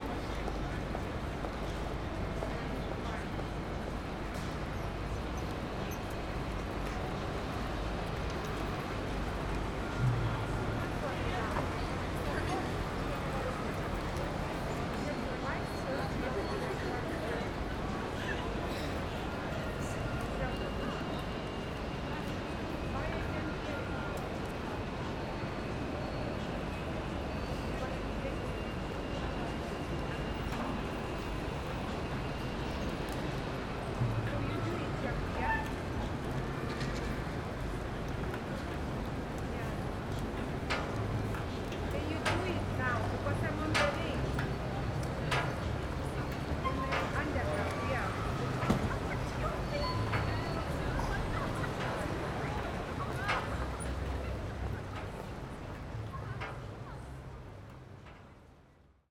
London, St Pancras - CCM41 / CCM8 / SD664